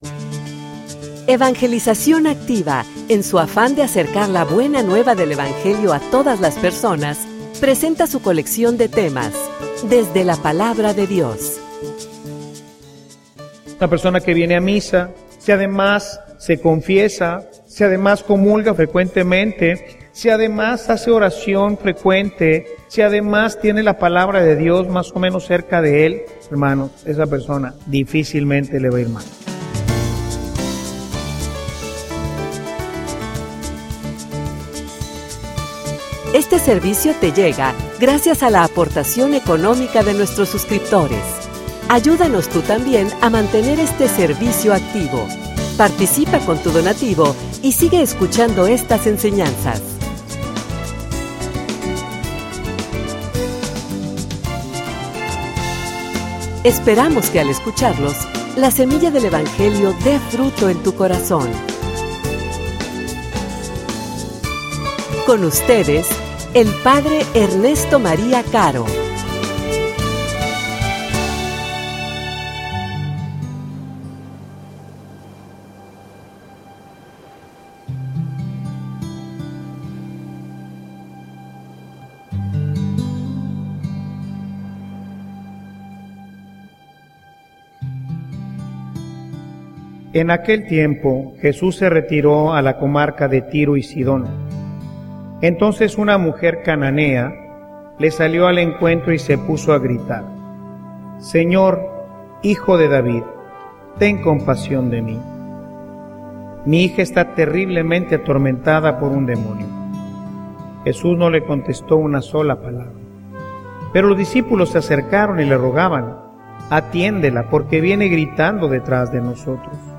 homilia_Primero_mi_familia.mp3